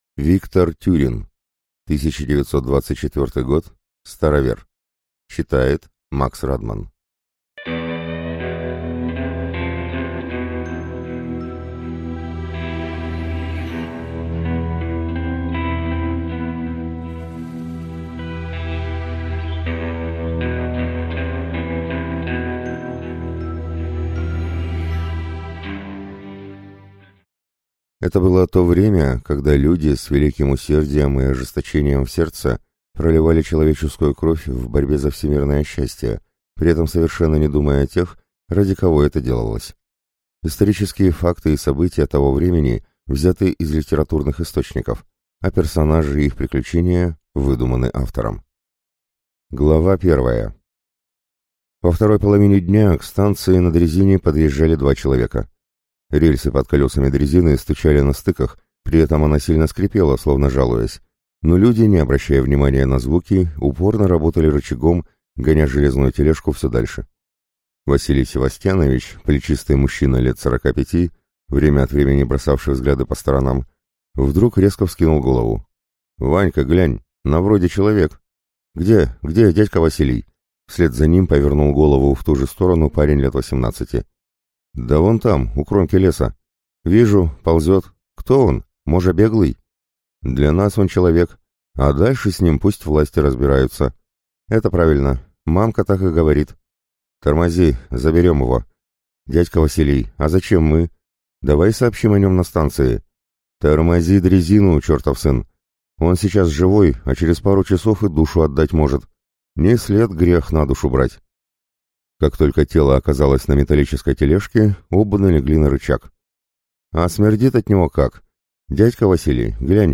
Аудиокнига 1924 год. Старовер | Библиотека аудиокниг